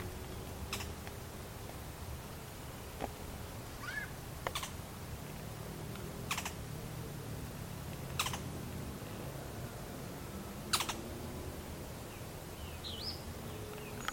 Tacuarero (Clibanornis dendrocolaptoides)
Nombre en inglés: Canebrake Groundcreeper
Fase de la vida: Adulto
Localidad o área protegida: Parque Provincial Araucaria
Condición: Silvestre
Certeza: Observada, Vocalización Grabada